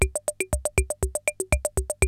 CR-68 LOOPS2 2.wav